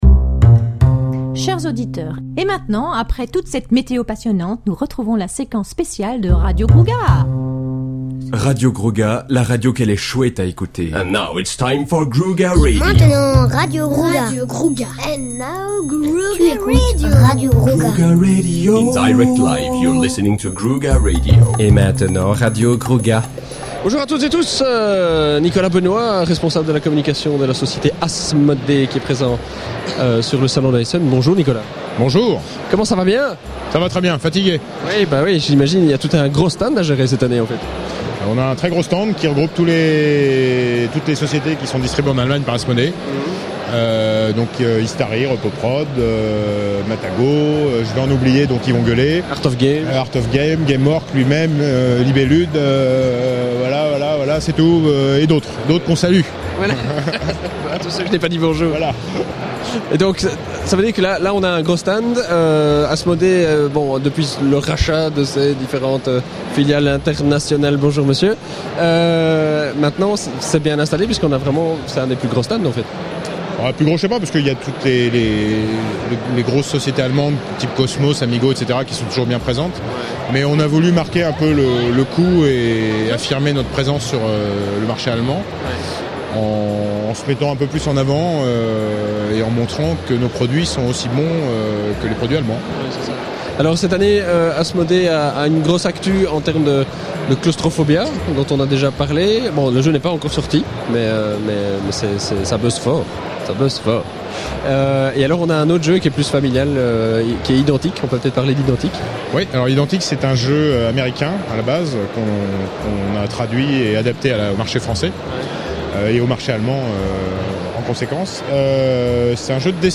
(enregistré lors du salon international du jeu de société Spiel 2009 à Essen/Allemagne)